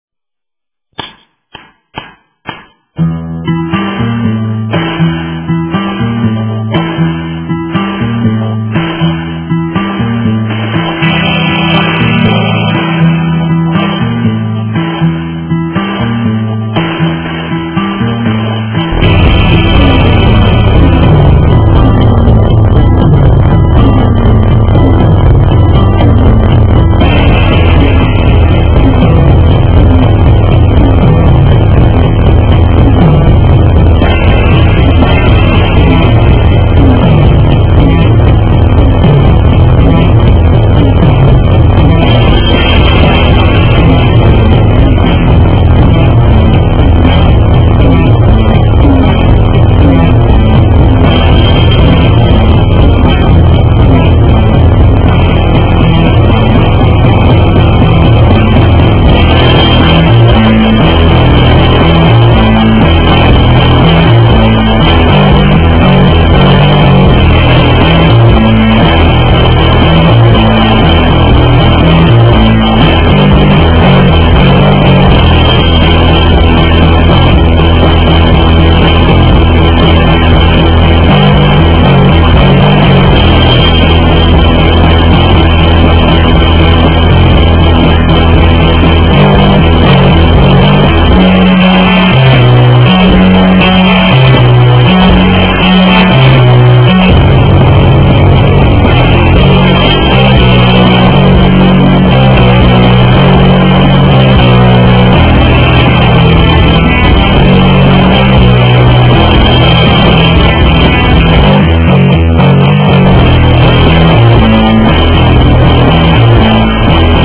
You will also need to get the palm muting just right to get the thick rhythm that "drives" the song along.
SOLO
solo.wma